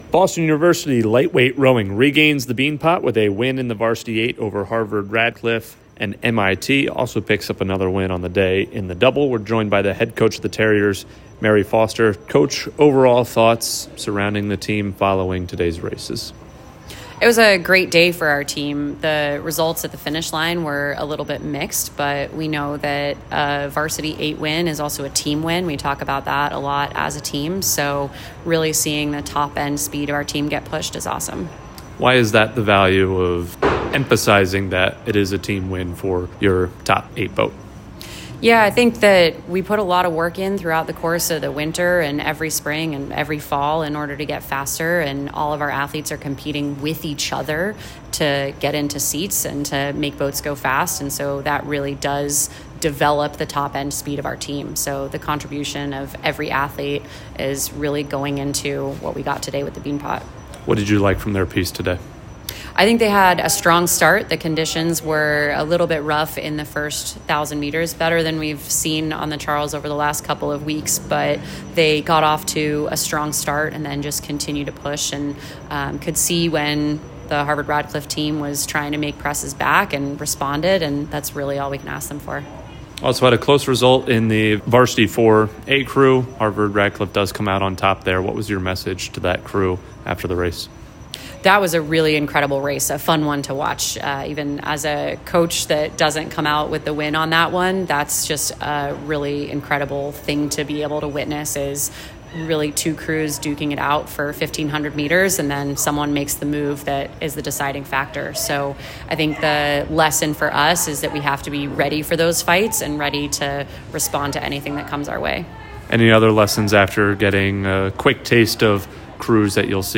LTROW_Beanpot_Postrace.mp3